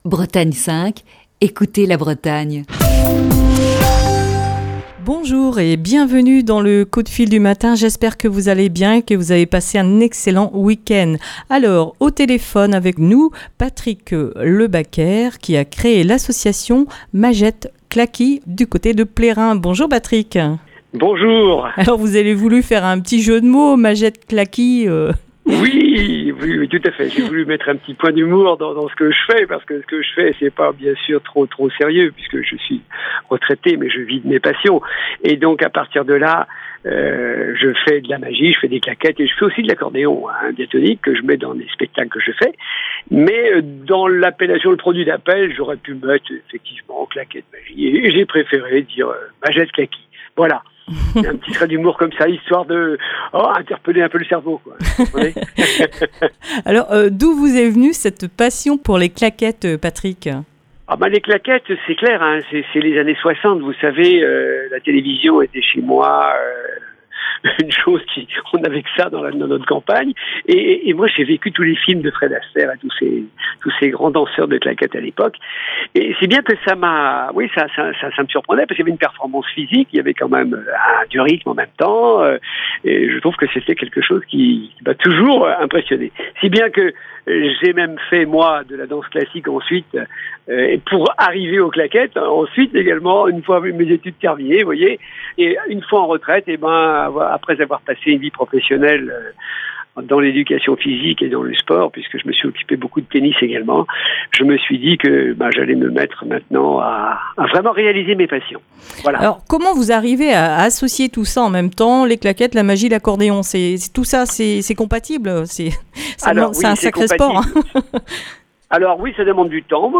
est au téléphone